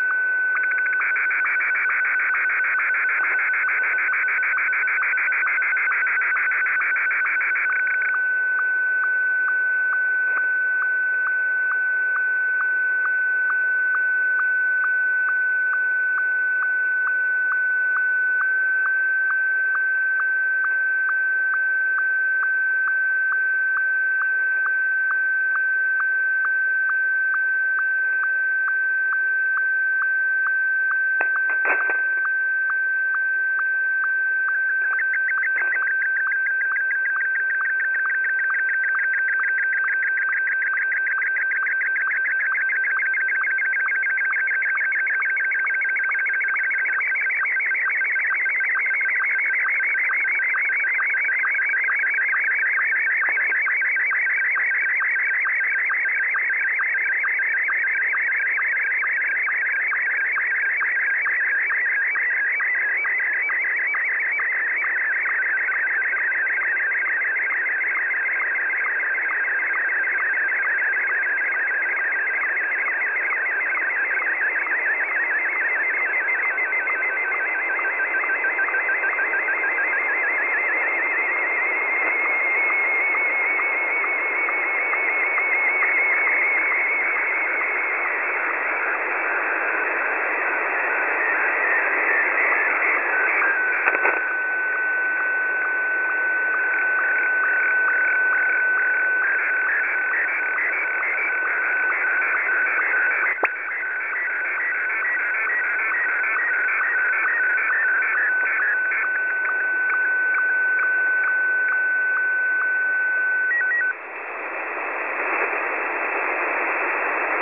websdr_recording_start_2025-02-12T22_53_34Z_8190.0kHz.wav websdr_recording_start_2025-02-12T22_53_34Z_8190.0kHz.wav 1.6 MB websdr_recording_start_2025-02-12T22_52_26Z_8190.0kHz.wav websdr_recording_start_2025-02-12T22_52_26Z_8190.0kHz.wav 798 KB